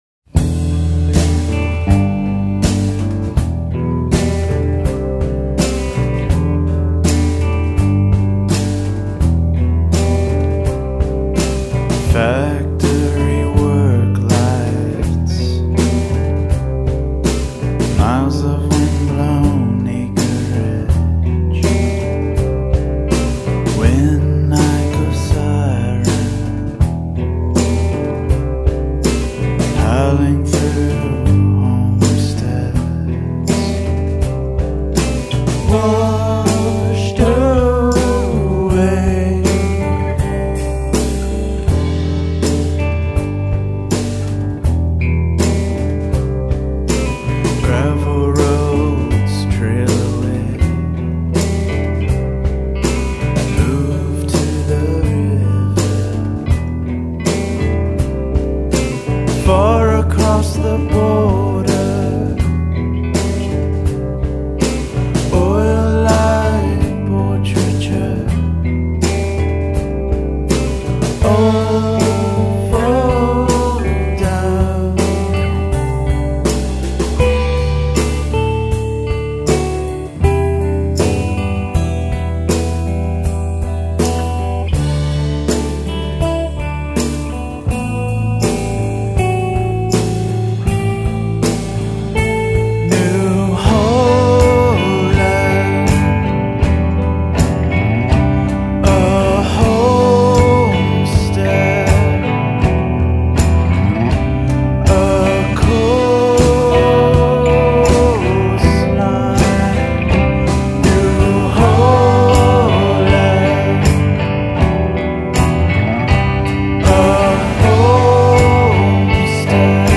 a slow meandering song with a simple pop sensibility